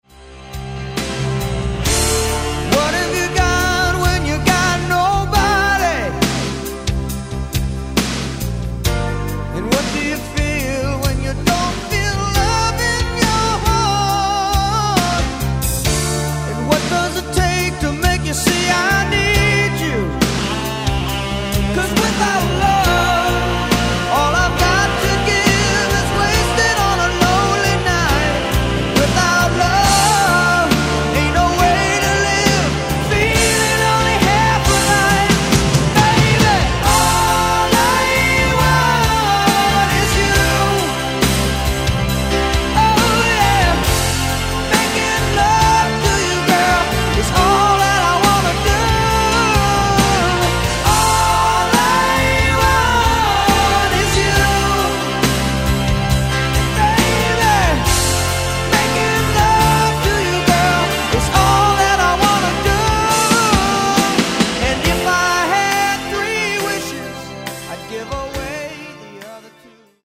mid-tempo AOR ballad